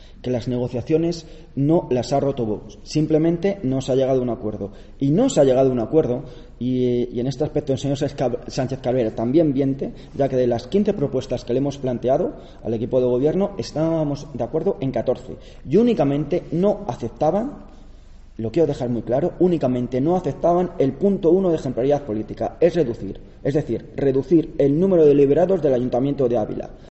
Ha sido en una rueda de prensa en la que los ediles de la formación han sido duros y categóricos en sus declaraciones.